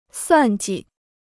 算计 (suàn ji): to reckon; to calculate.